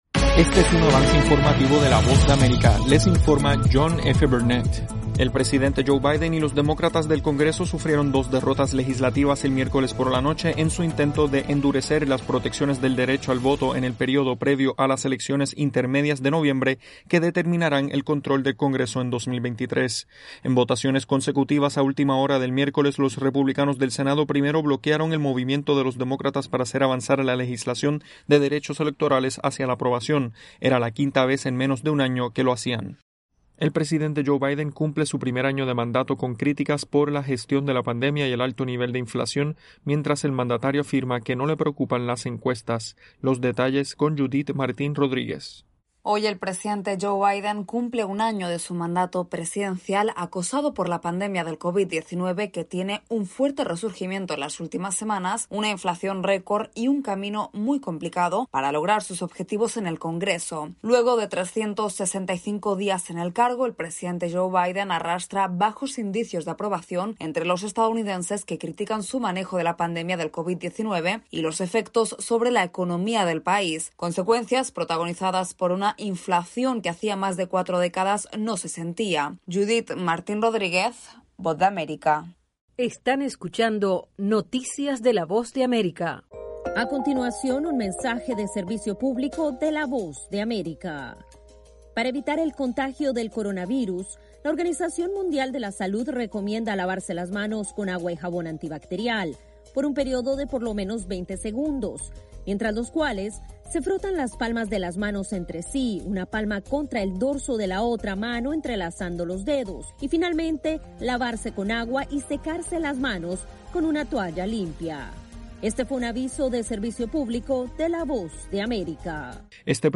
Desde los estudios de la Voz de América